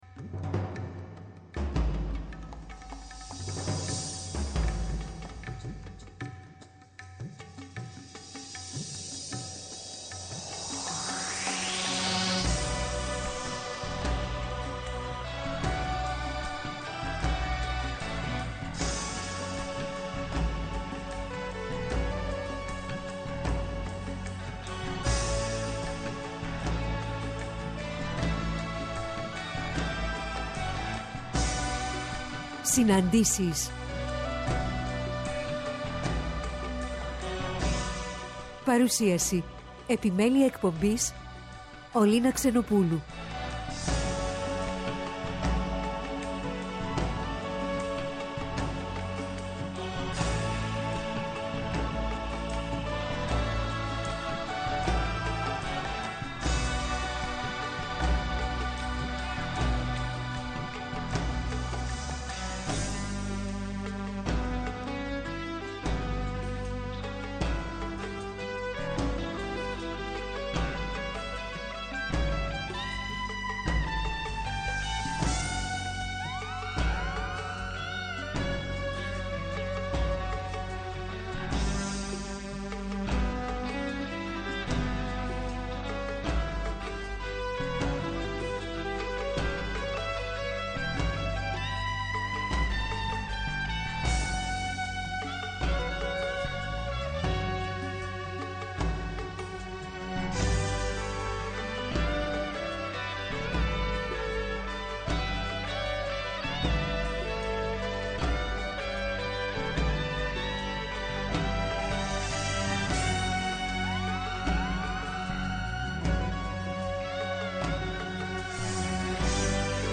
Στις Συναντήσεις σήμερα 4-5 το απόγευμα, καλεσμένες τηλεφωνικά :